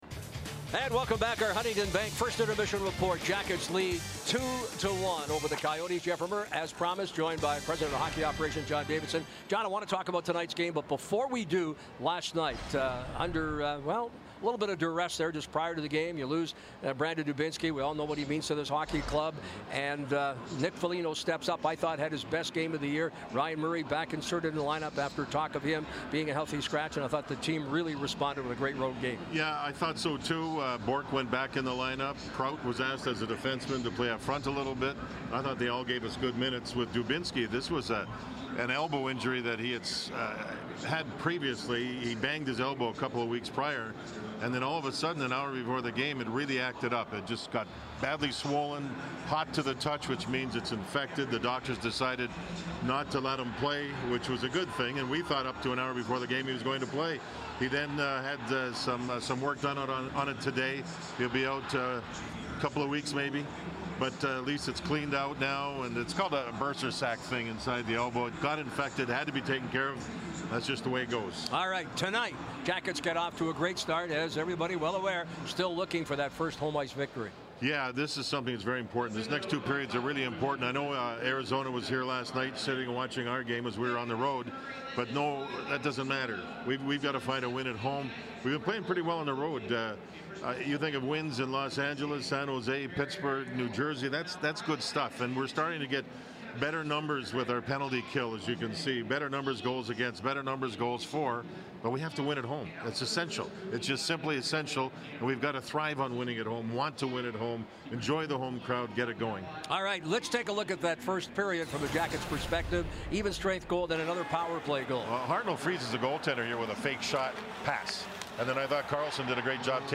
Jeff Rimer chats with John Davidson during intermission of the Blue Jackets vs. Arizona Coyotes on November 14, 2015. Jeff and JD go into Dubi's status and potential rule changes that could increase goal-scoring.